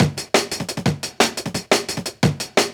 drums01.wav